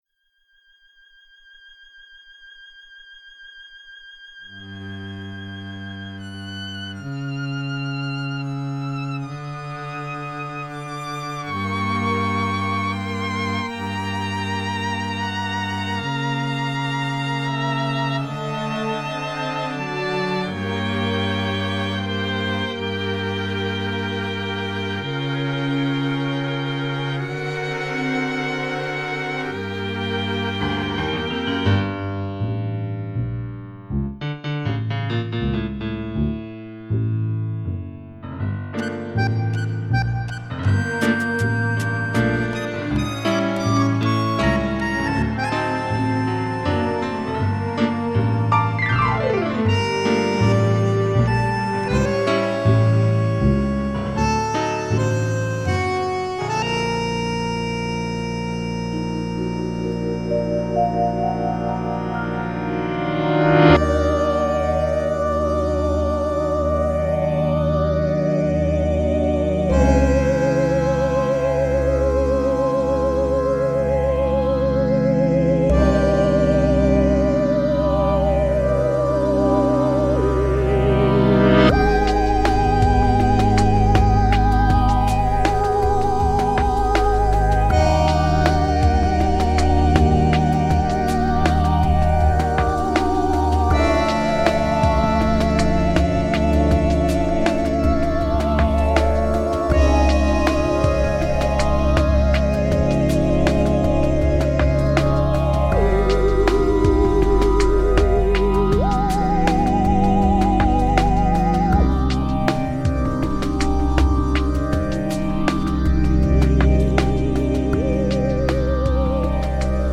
This album was composed as a film soundtrack
Electronic Tango.
(String quartet, loops, piano, vibes & synthesizers).